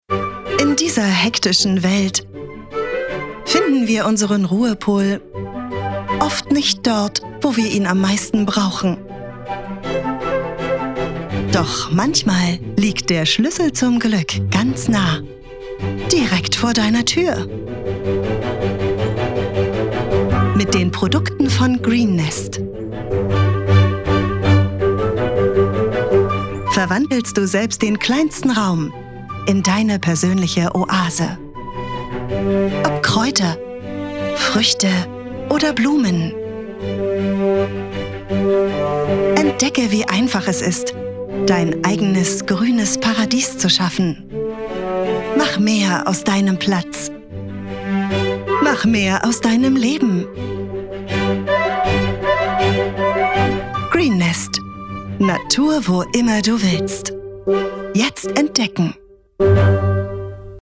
Sprecherin in Berlin, klare und warme Stimme, Mezzosopran, spricht Werbung, Dokumentation, Imagefilme, Audioguides, Hörbuch, Hörspiel, E-Learning, Games, Erklärfilm, Voice over, Telefonansage
Sprechprobe: eLearning (Muttersprache):